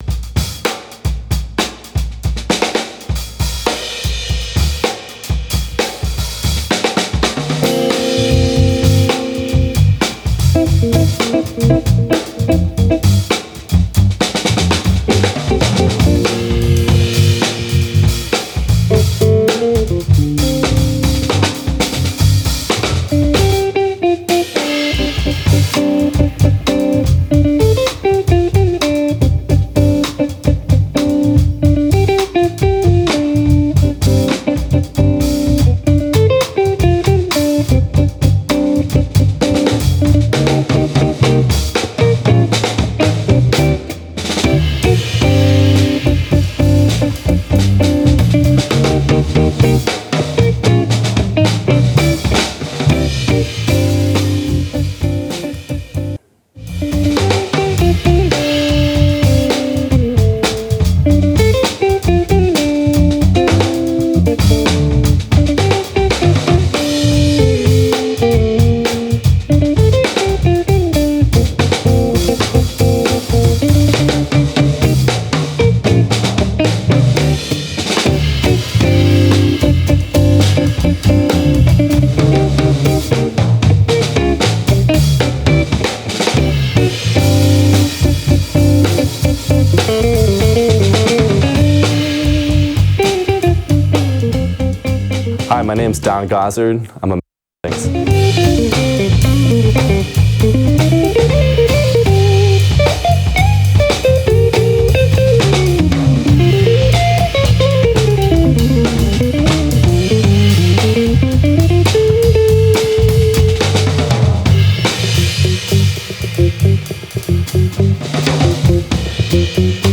partido alto guitar trio
partido-alto-guitar-trio.mp3